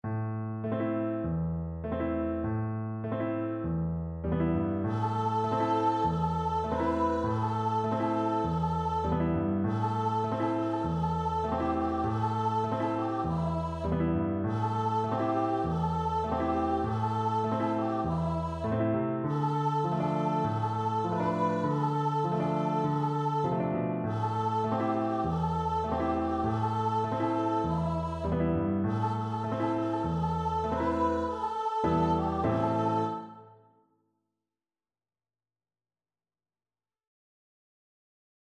Free Sheet music for Voice
Voice
Moderato
4/4 (View more 4/4 Music)
E5-B5
A minor (Sounding Pitch) (View more A minor Music for Voice )
Traditional (View more Traditional Voice Music)
kagome_kagome_VOICE.mp3